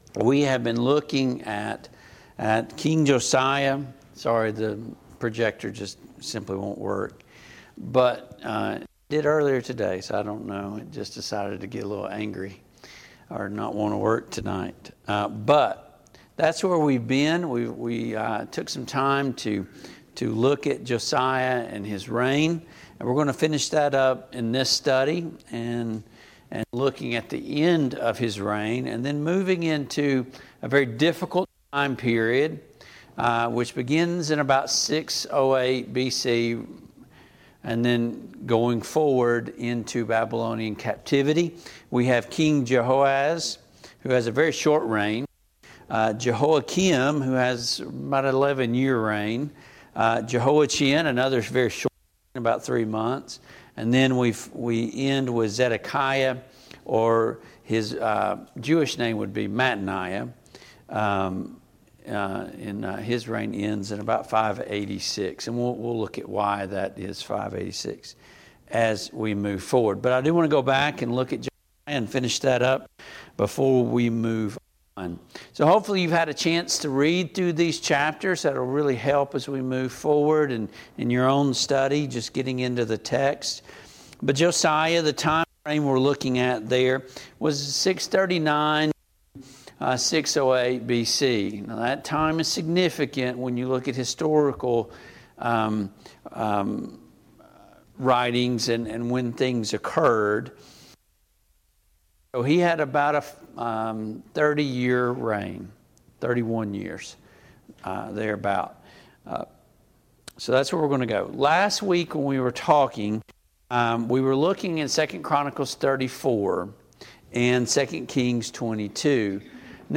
The Kings of Israel and Judah Passage: 2 Kings 22, 2 Kings 23, 2 Kings 24, 2 Chronicles 34, 2 Chronicles 35 Service Type: Mid-Week Bible Study